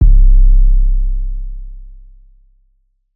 Metro 808 4.wav